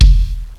Castle kick.wav